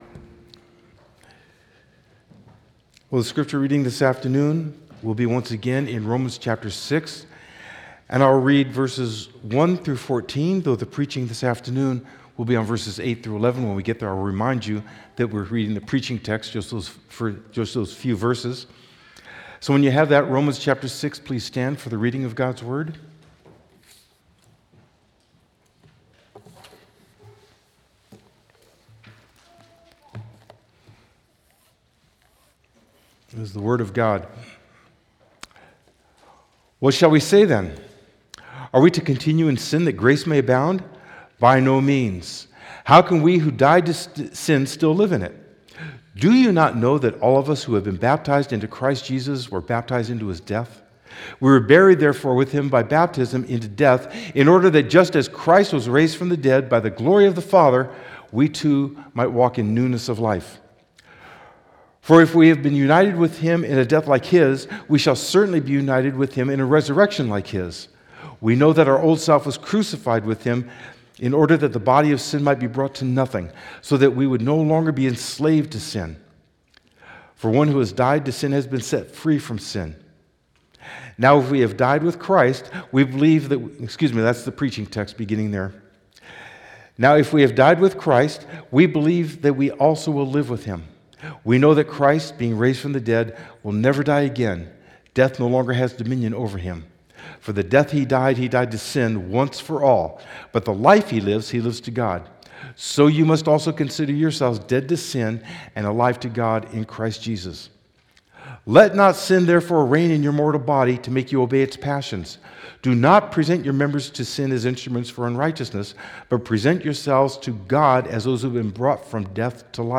The Gospel Of Christ Preacher